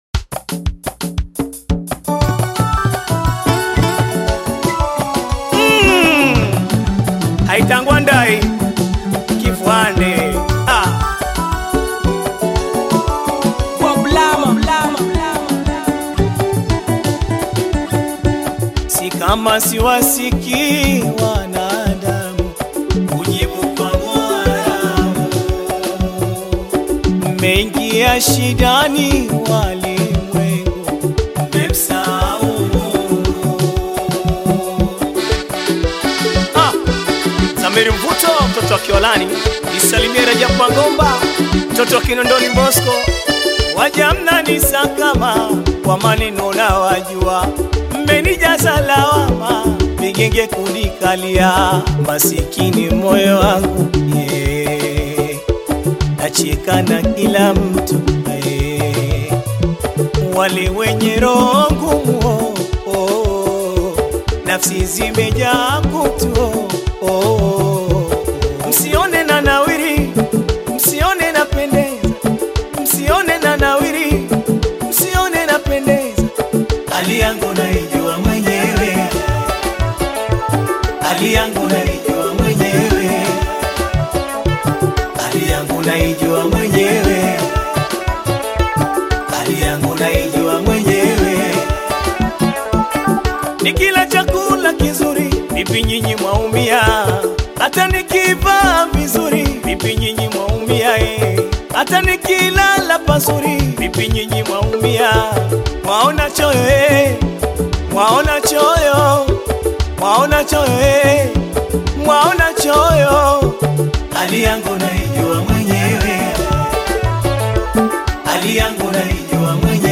Taarab music track